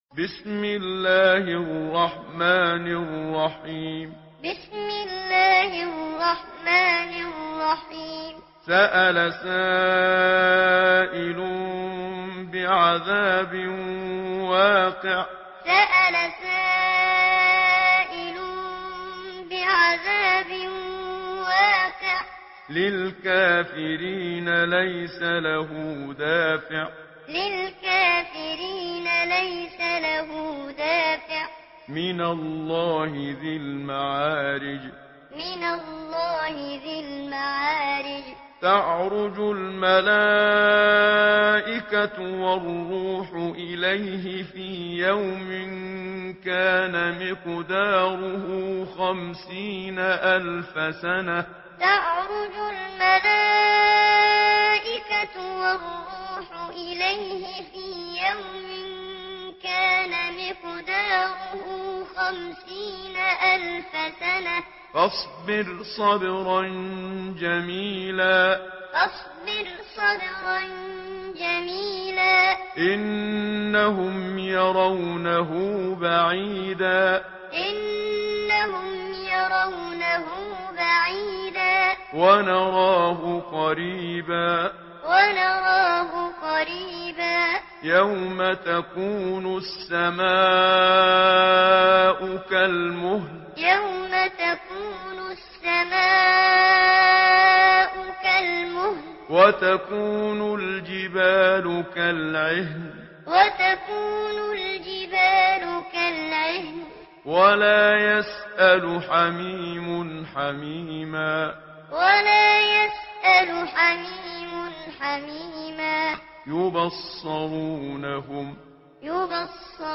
Surah Mearic MP3 by Muhammad Siddiq Minshawi Muallim in Hafs An Asim narration. Listen and download the full recitation in MP3 format via direct and fast links in multiple qualities to your mobile phone.